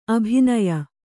♪ abhinaya